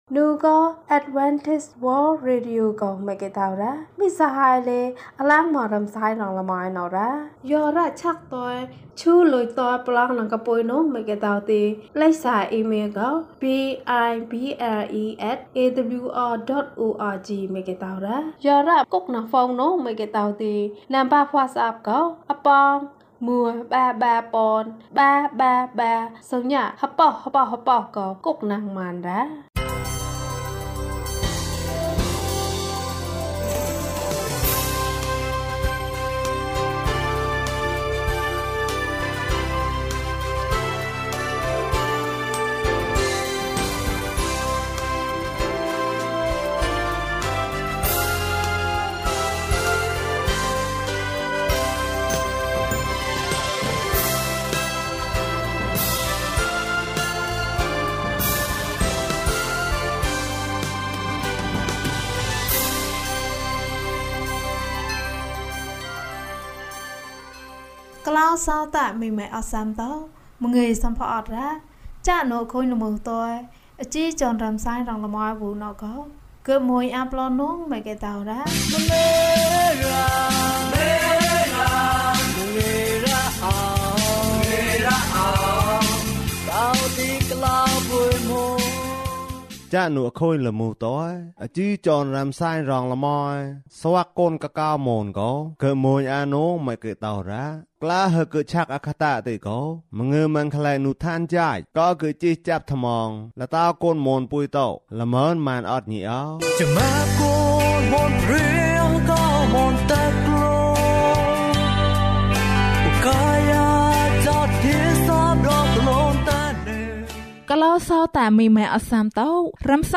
လူငယ်များအတွက် သတင်းစကား။၀၂ ကျန်းမာခြင်အးကြောင်းအရာ။ ဓမ္မသီချင်း။ တရားဒေသနာ။